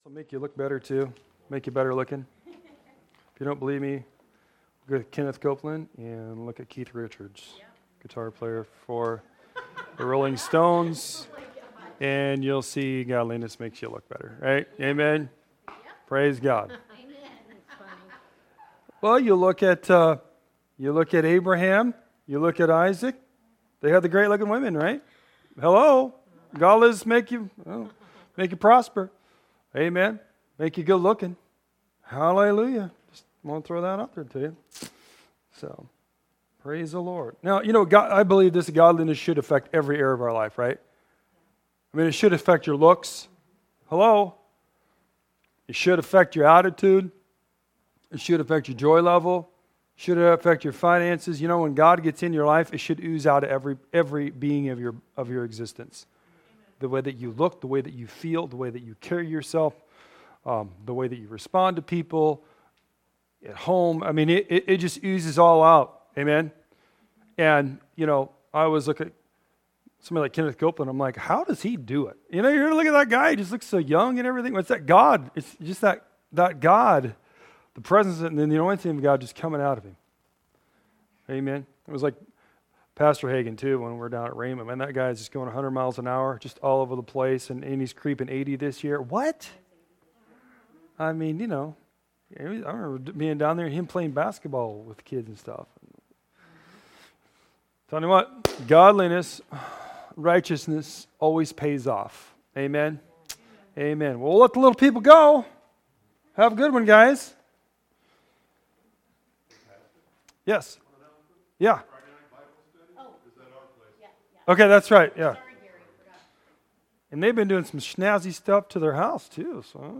A collection of sermons/pastoral messages from 2018-2022.